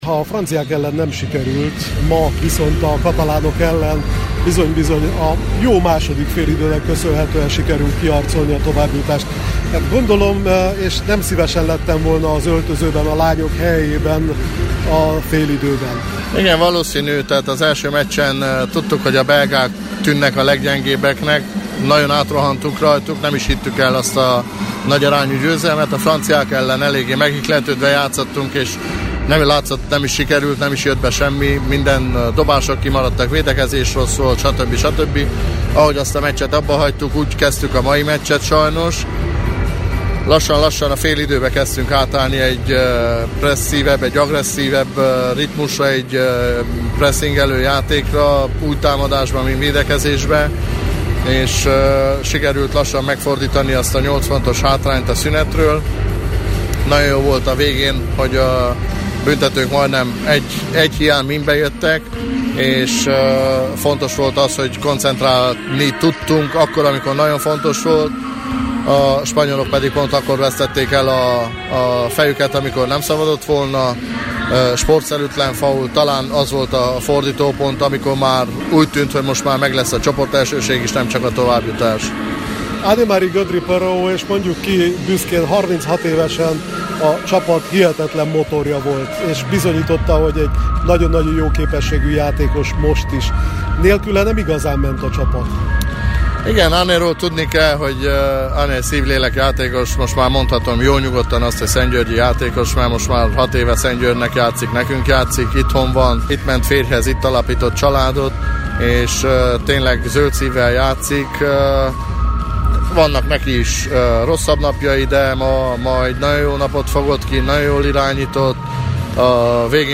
értékelő beszélgetésből